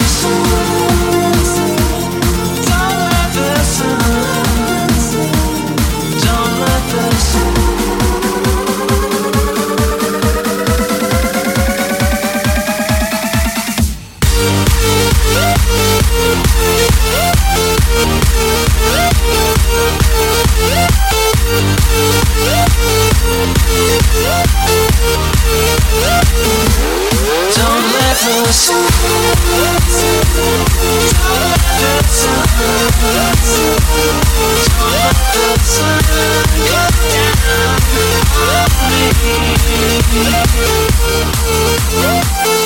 Genere: deep house, house, club, remix